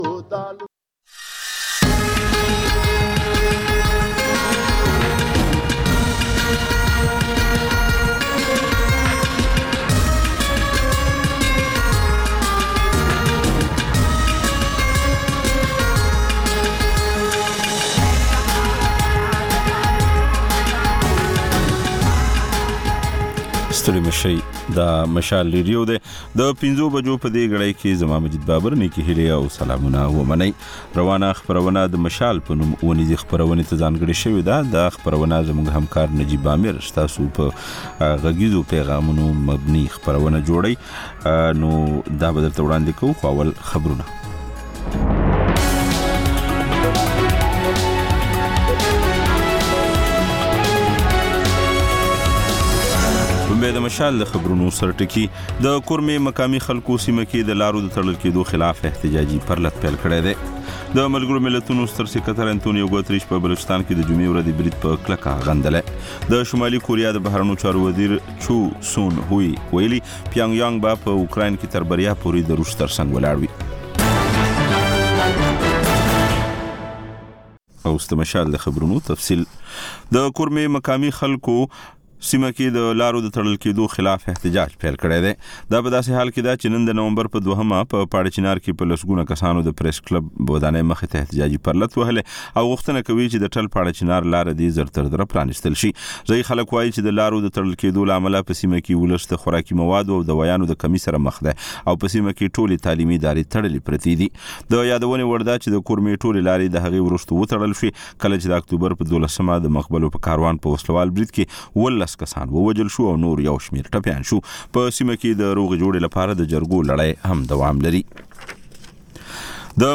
د مشال راډیو ماښامنۍ خپرونه. د خپرونې پیل له خبرونو کېږي، بیا ورپسې رپورټونه خپرېږي.